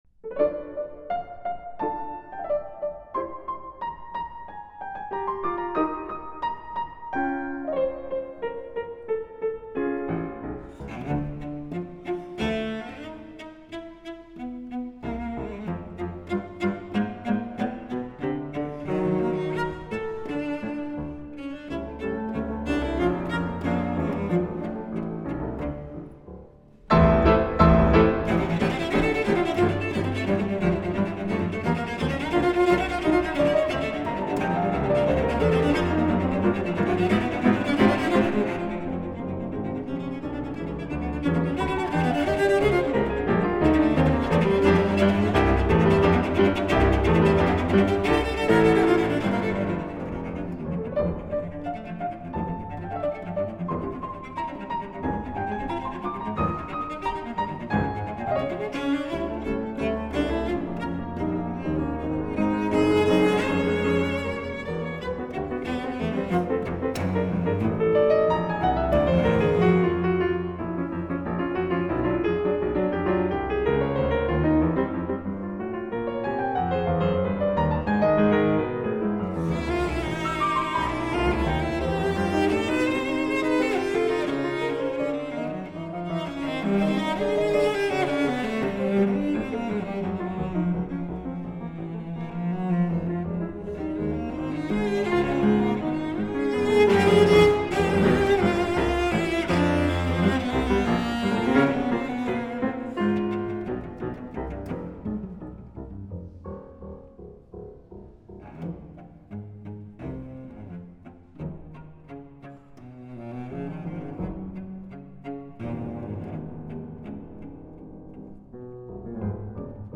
Трек размещён в разделе Зарубежная музыка / Классика.